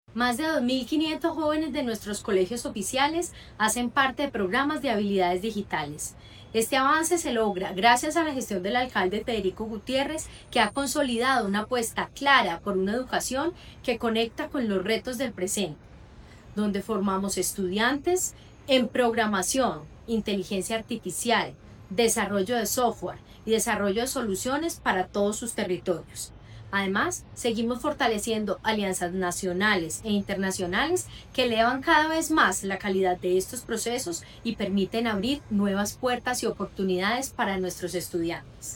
Declaraciones de la secretaria de Educación, Carolina Franco Giraldo
Declaraciones-de-la-secretaria-de-Educacion-Carolina-Franco-Giraldo-1.mp3